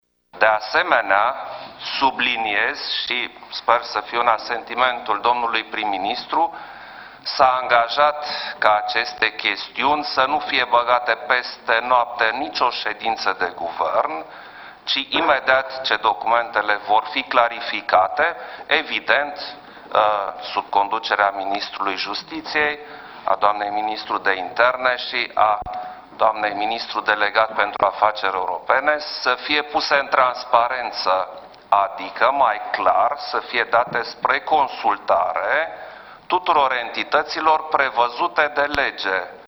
Anunţul a fost făcut de preşedintele Klaus Iohannis în deschiderea şedinţei de guvern: